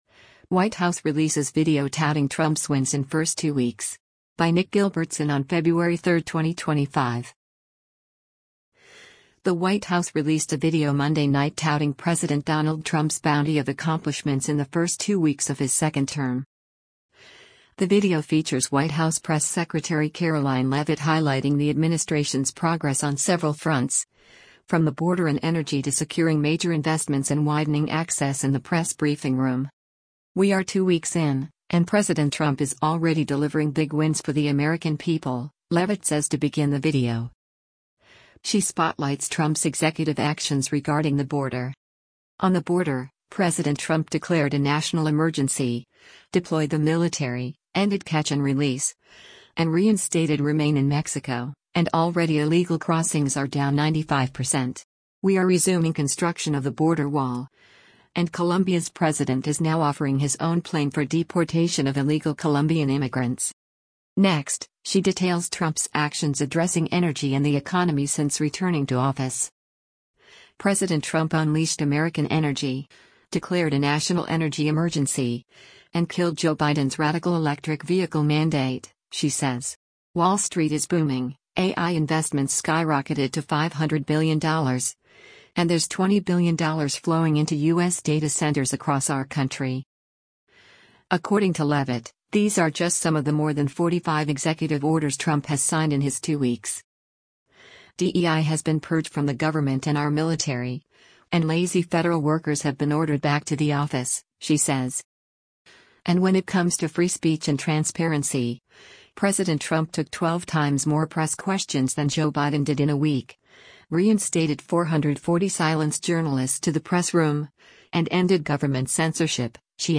The video features White House press secretary Karoline Leavitt highlighting the administration’s progress on several fronts, from the border and energy to securing major investments and widening access in the press briefing room.